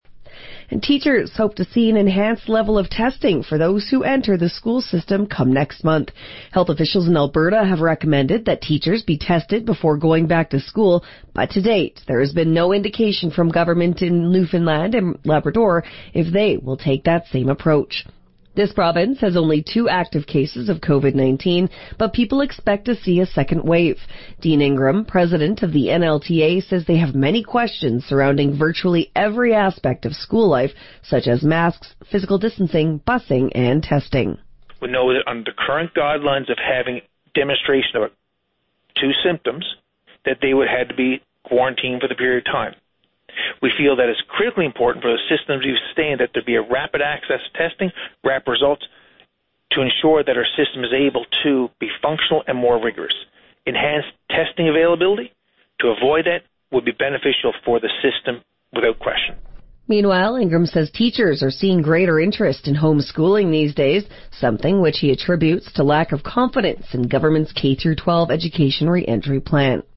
Media Interview - VOCM 6am News Aug. 14, 2020